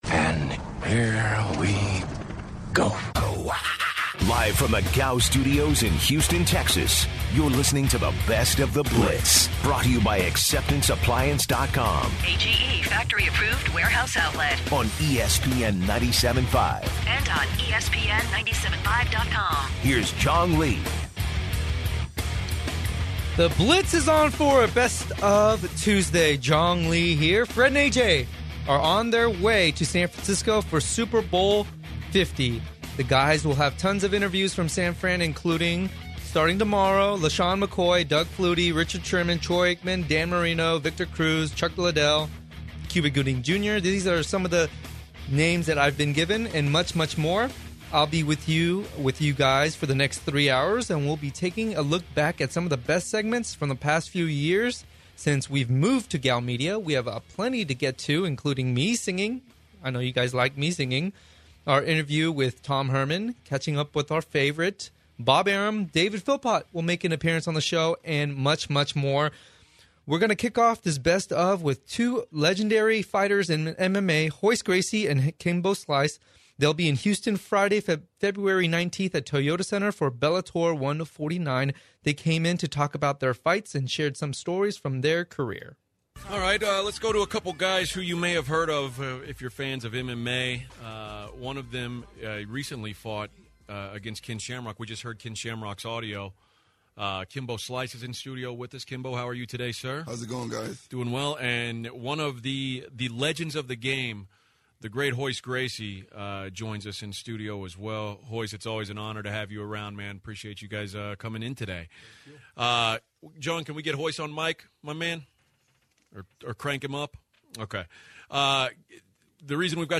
Hour 1 begins with previous interviews with MMA fighters Kimbo Slice and Royce Gracie and when they discussed fighting and stories about both of their careers and what they’ve been up to. Next is an interview with JJ Watt from about 4 years ago at Superbowl XLVI when he was just developing into the player he is today.